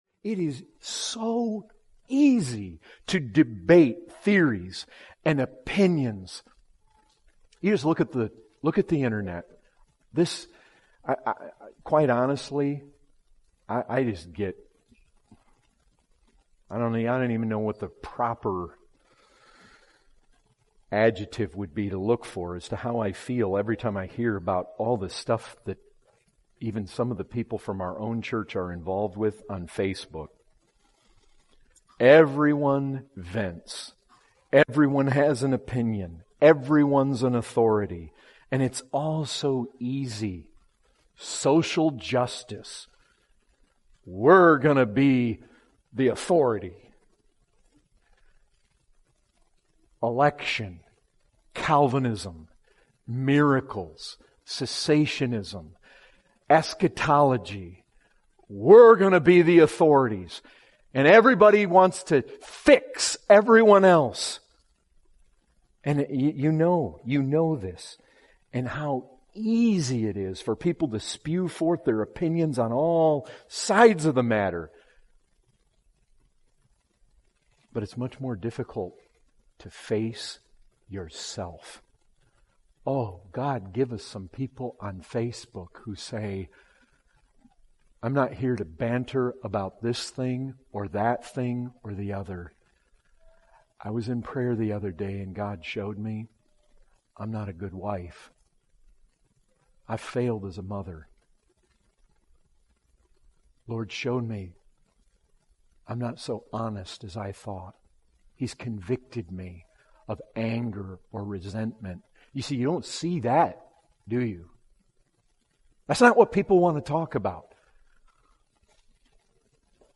Category: Excerpts